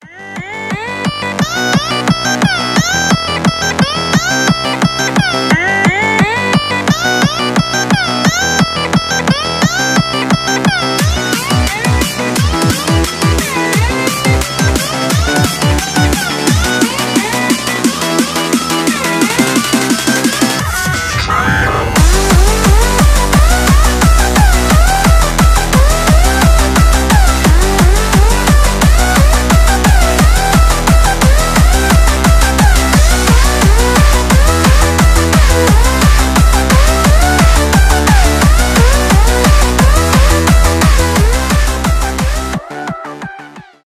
happy hardcore
энергичные
быстрые